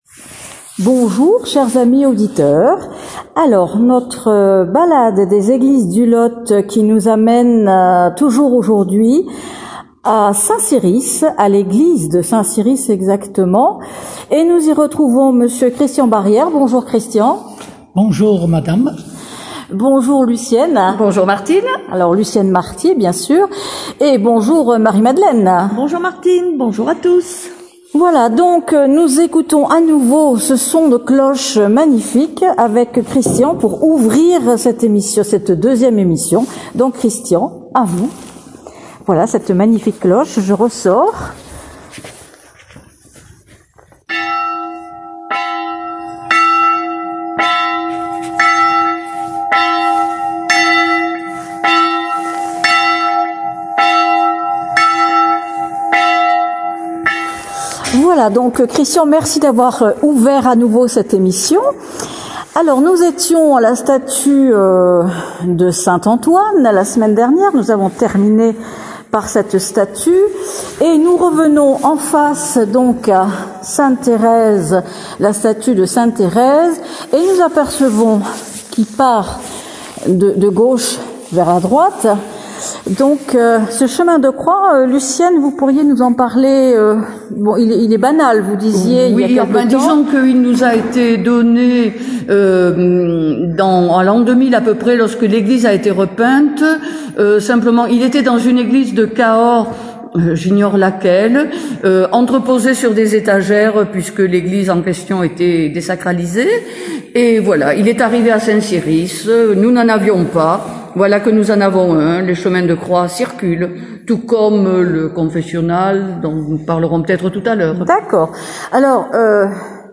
La cloche sonne !